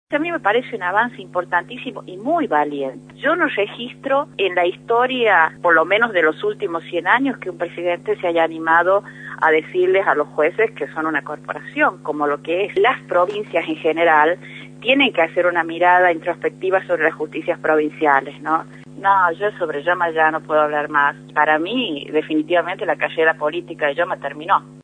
Teresita Luna, senadora nacional, por Radio La Red